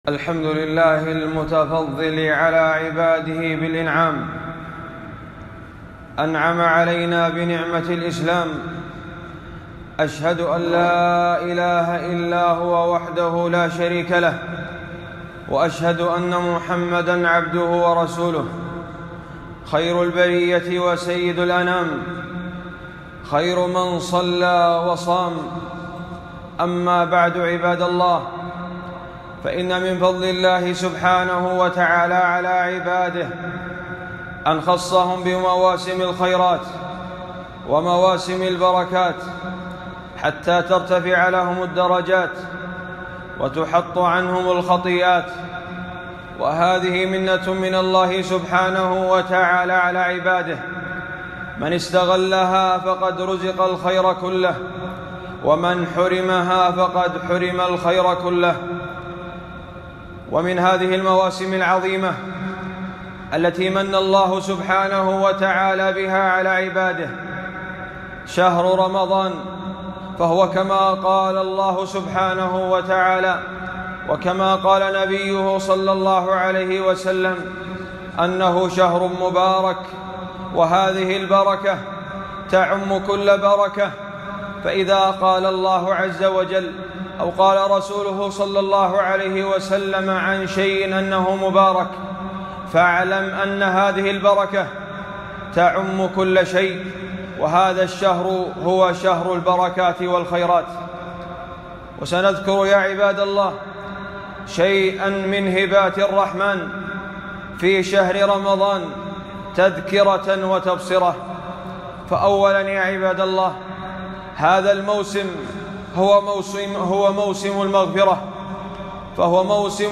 خطبة - من هبات الرحمن في شهر رمضان